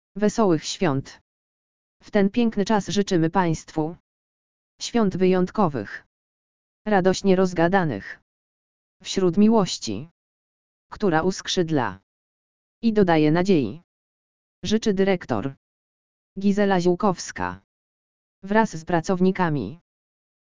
audio_lektor_zyczenia_swiateczne_2021.mp3